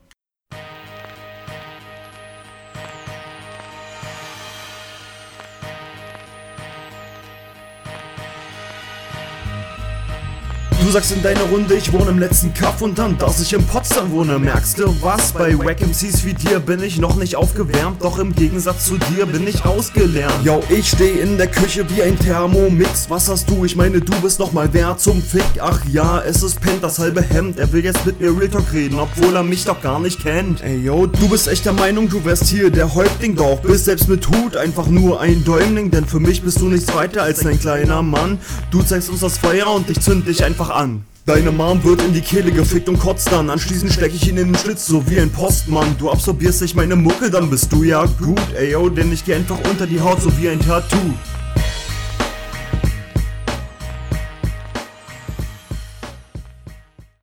holperst beim einstieg bissl. reime zu simpel. konter kommen nicht punchig